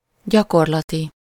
Ääntäminen
Ääntäminen Tuntematon aksentti: IPA: /ˈɟɒkorlɒti/ Haettu sana löytyi näillä lähdekielillä: unkari Käännös Ääninäyte Adjektiivit 1. practical US 2. real UK US 3. empirical US 4. applied US Luokat Adjektiivit